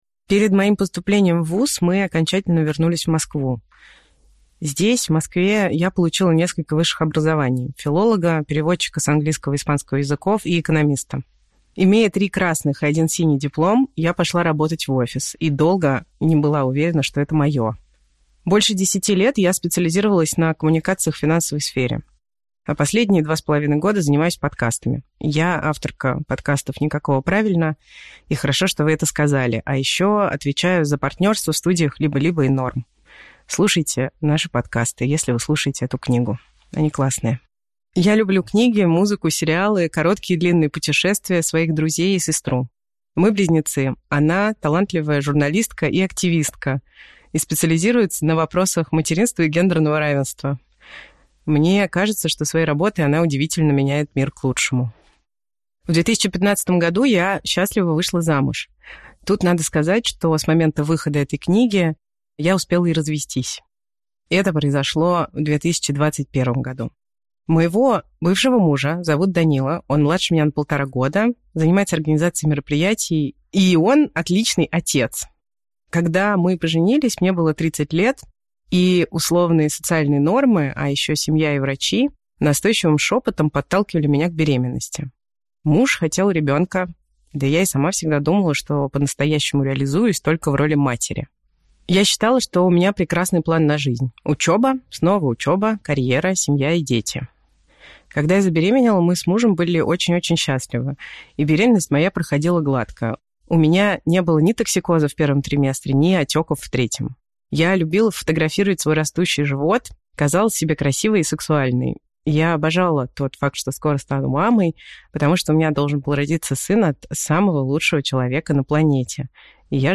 Аудиокнига Не просто устала. Трудная правда о послеродовой депрессии | Библиотека аудиокниг